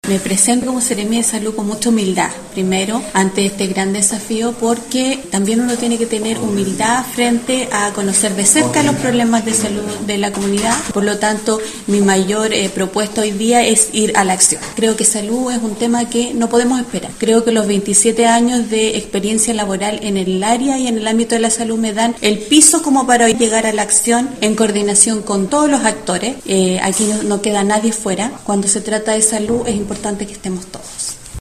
La Seremi Solís cuenta con experiencia tanto en la salud pública como privada, además de experiencia en los ámbitos de la docencia universitaria y gestión de departamentos de salud a nivel primario y comentó que enfrenta este nuevo desafío con humildad y con confianza en su experiencia en salud pública: